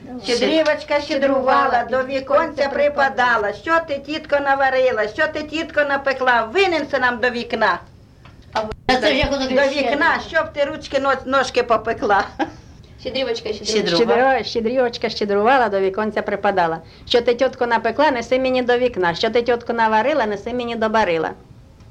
ЖанрЩедрівки
Місце записус. Ізюмське, Борівський район, Харківська обл., Україна, Слобожанщина